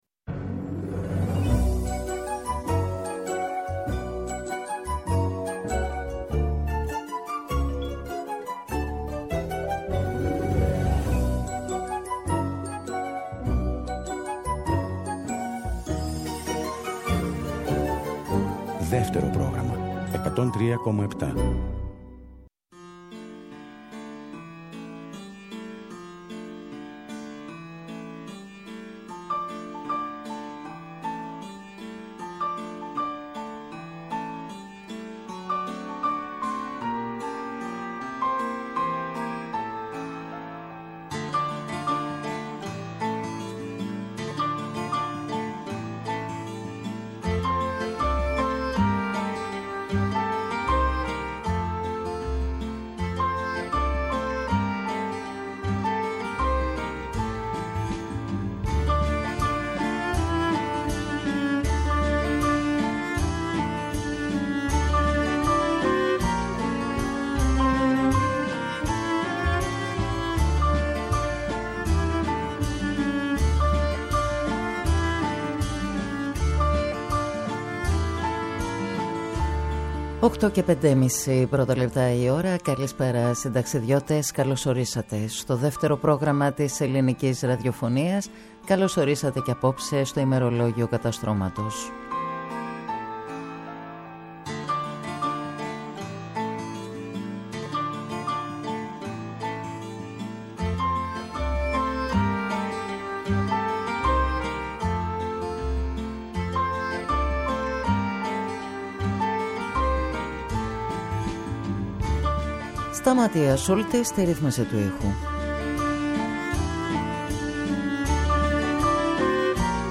Συνταξιδιώτες οι ακροατές, ούριος άνεμος η μουσική και τα τραγούδια.